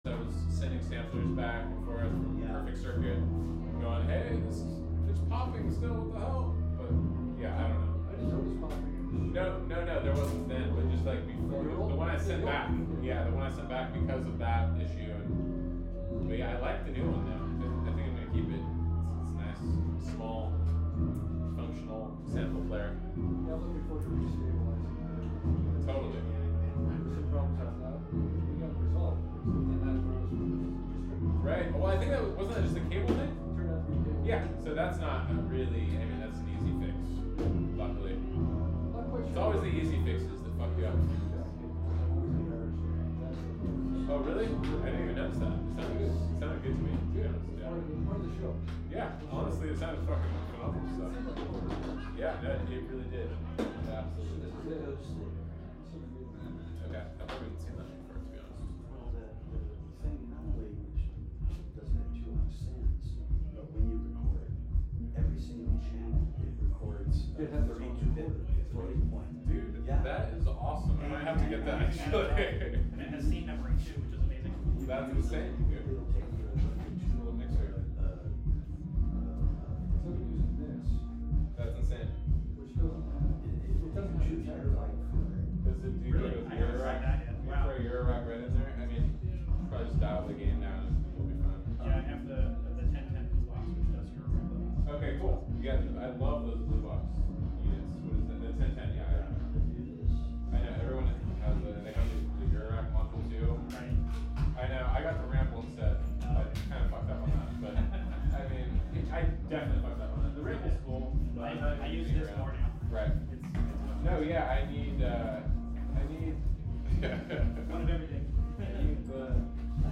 Live from Woodstockhausen Music Festival
Live from Woodstockhausen Music Festival: Woodstockhausen 25' West (Audio) Aug 29, 2025 shows Live from Woodstockhausen Music Festival Woodstockhausen is a bi-coastal experimental music festival.